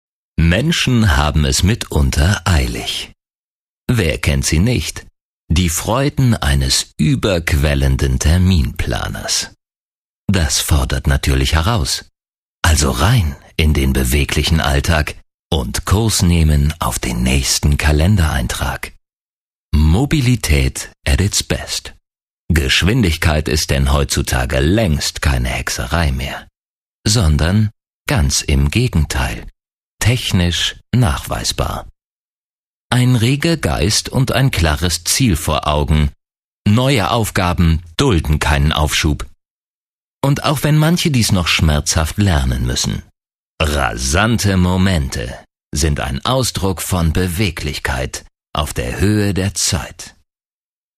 deutscher Sprecher für hörspiel, synchron, doku, voice-over, industrie, werbung, feature etc.
Sprechprobe: Industrie (Muttersprache):